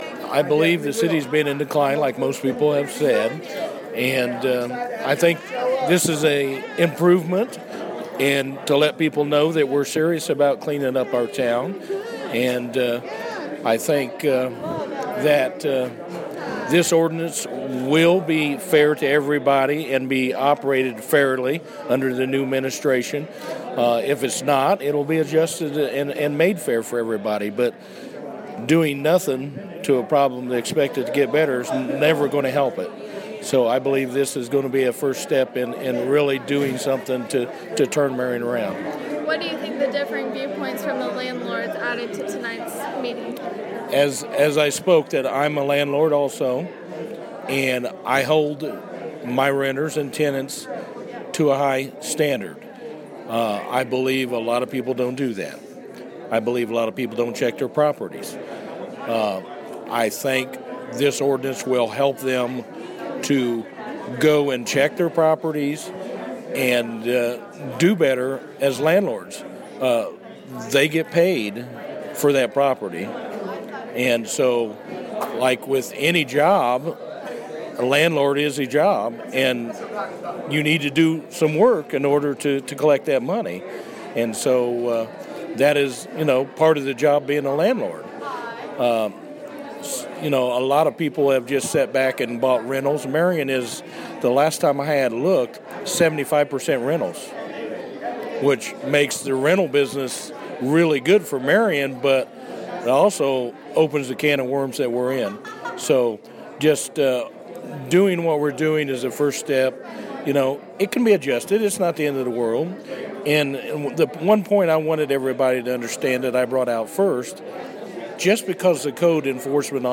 City Council Member and Marion landlord speaks in favor of the proposed changes of Marion's Trash Ordinance.